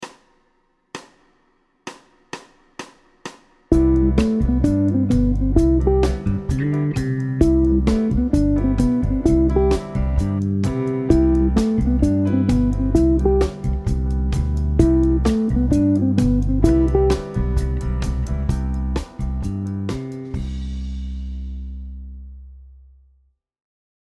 C Encapsulation de la Fondamentale avant de jouer l’arpège montant de C Maj7.